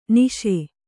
♪ niśa